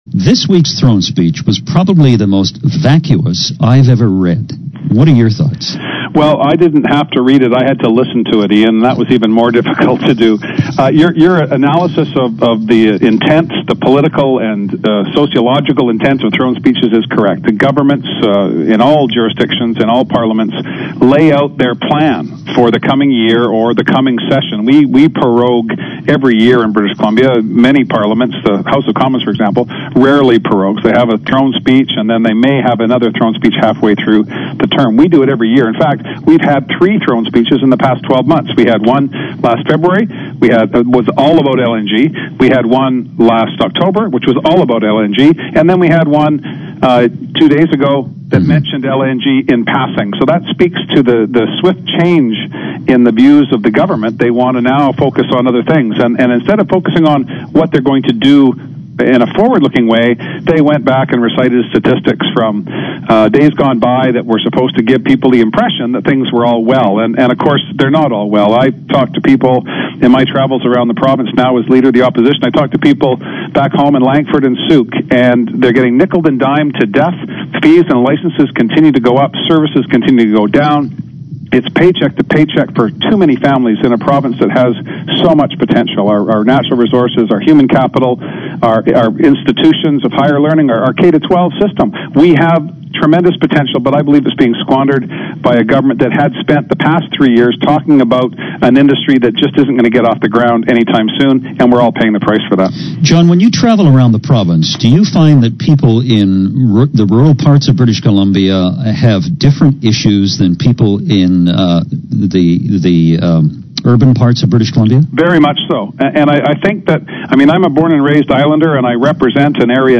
There's a man with a fire in his belly! It is always a pleasure to hear a politician who can speak clearly and intelligently about important issues.